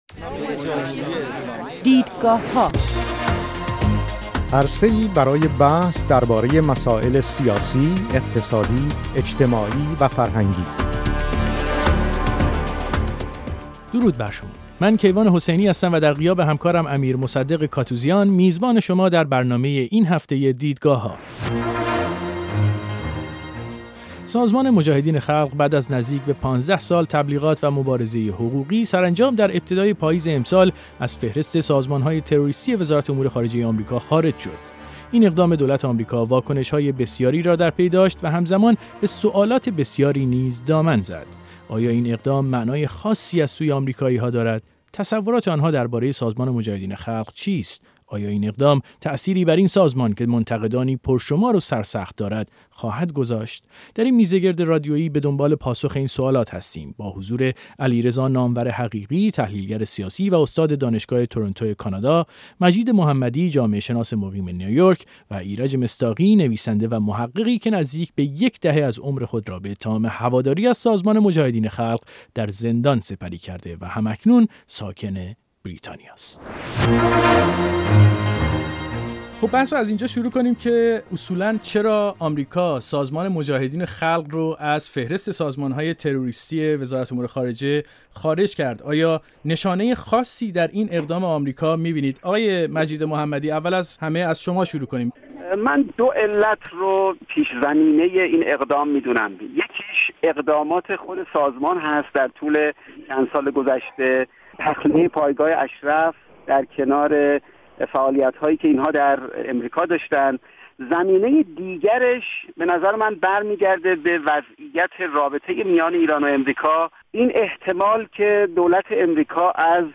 میزگرد رادیویی «دیدگاهها» را بشنوید